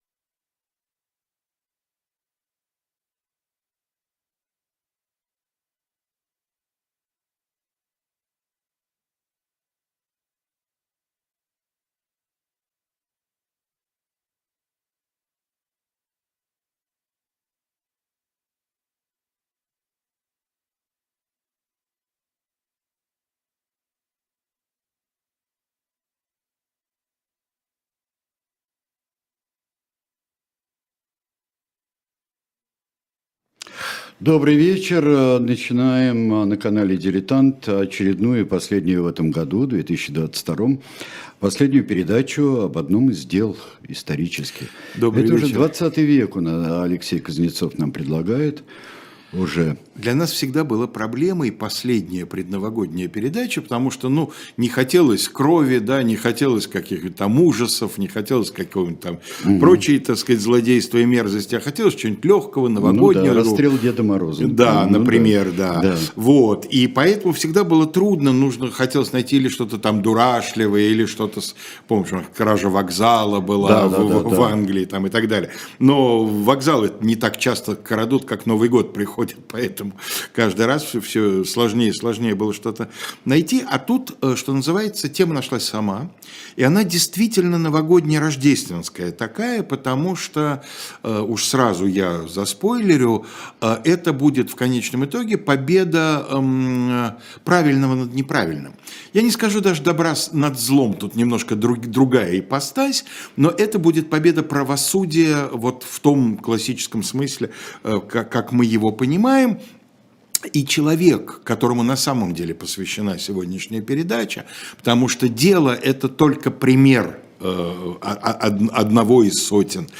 Сергей Бунтман журналист